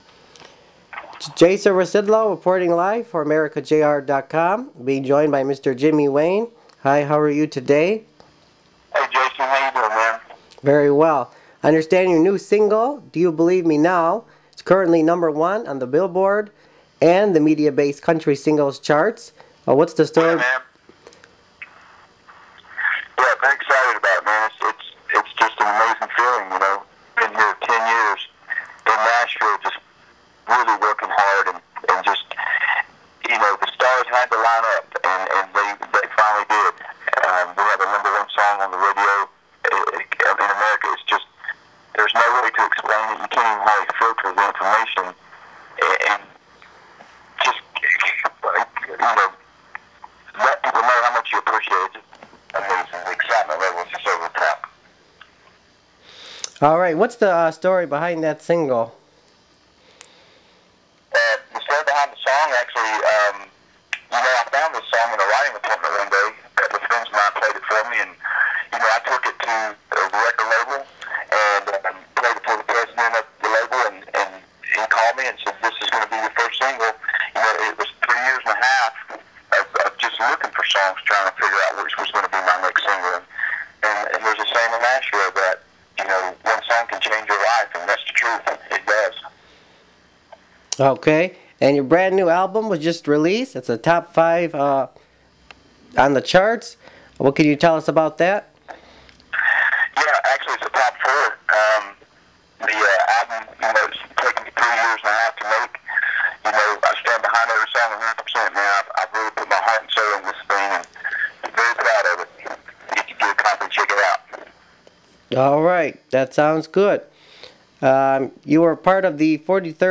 • Exclusive Interview with Valory recording artist Jimmy Wayne (9/9/08)